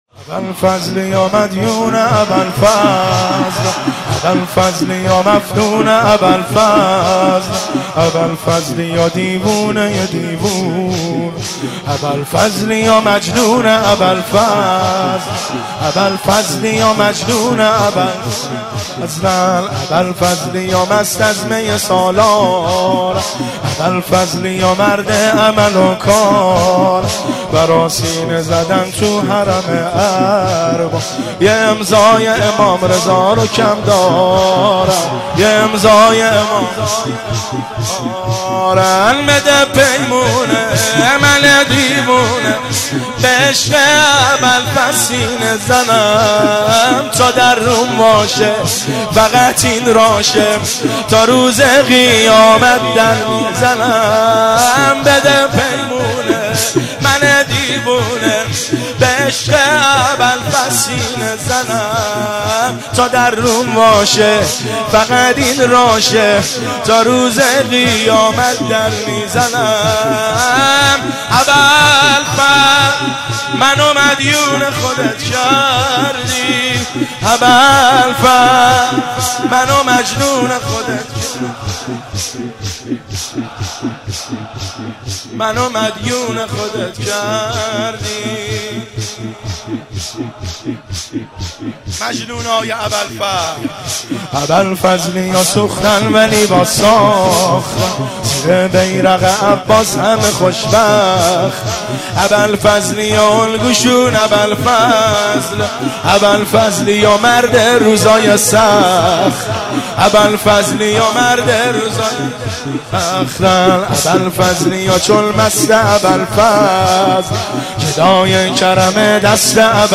هیئت عاشقان قمر بنی هاشم تهران